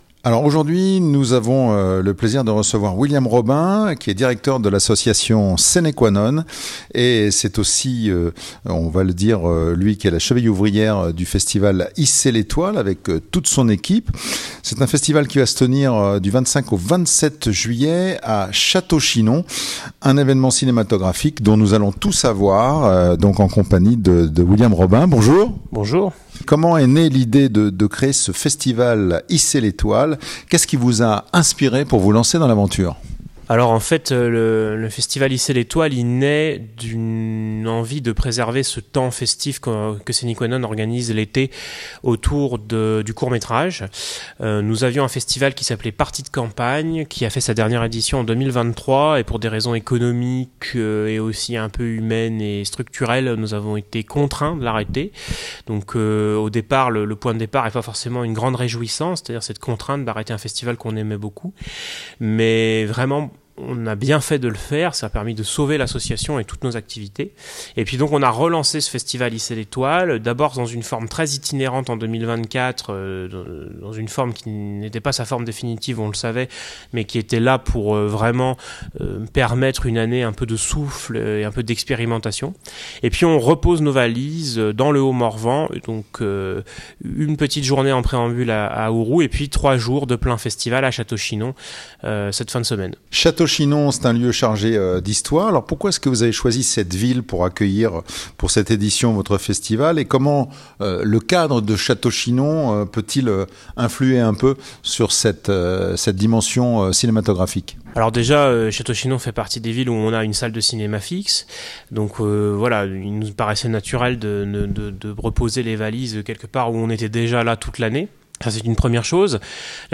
%%Les podcasts, interviews, critiques, chroniques de la RADIO DU CINEMA%% La Radio du Cinéma Le festival « Hissez les Toiles » déploie son cinéma en plein cœur du Morvan Podcasts de LA RADIO DU CINEMA 21 juillet 2025 La Radio du Cinéma Du 25 au 27 juillet, Château-Chinon en région Bourgogne-Franche-Comté devient le théâtre d’un festival aussi accessible que cinéphile : Hissez les Toiles. 21 juillet 2025 Découvrez les meilleurs podcasts sur le cinéma avec la Radio du Cinéma.